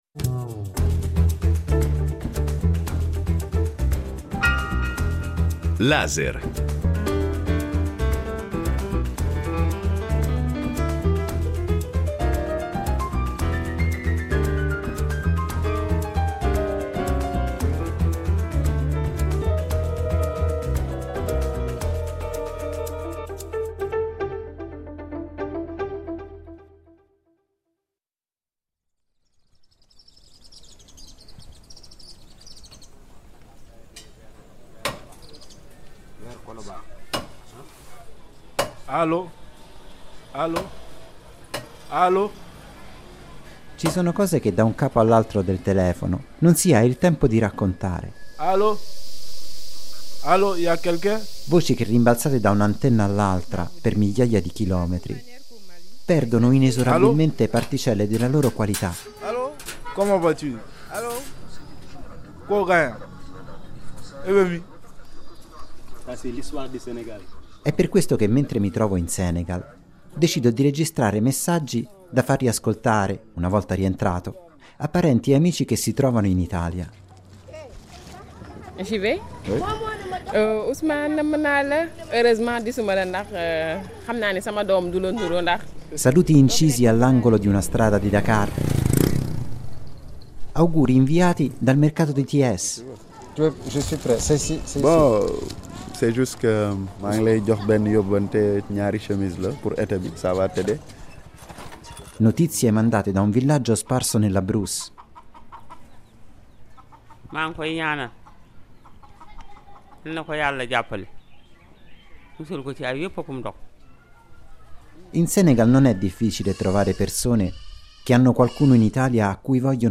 Cartoline sonore con incisi saluti e auguri da far ascoltare a parenti e amici che si trovano in Italia. Messaggi registrati all'angolo di una strada di Dakar, nella campagna intorno a Mbour, al mercato di Thiès e che parlano di un'intera generazione di emigrati.